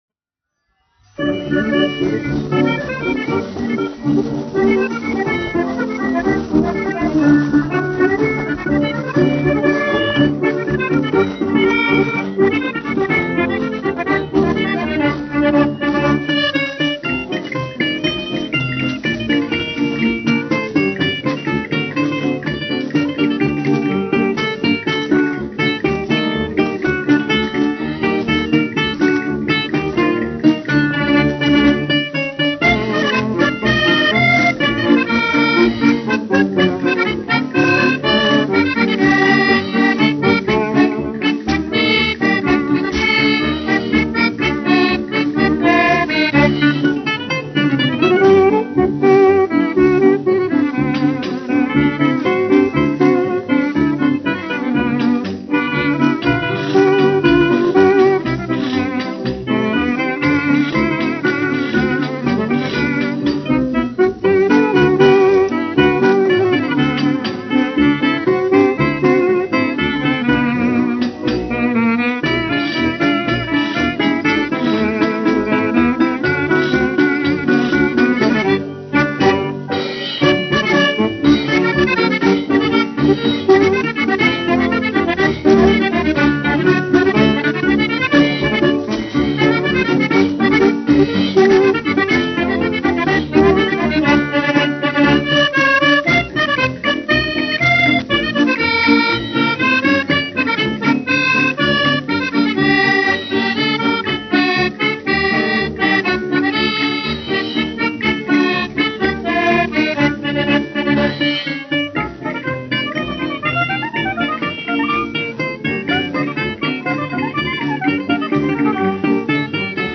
1 skpl. : analogs, 78 apgr/min, mono ; 25 cm
Populārā instrumentālā mūzika
Sarīkojumu dejas
Latvijas vēsturiskie šellaka skaņuplašu ieraksti (Kolekcija)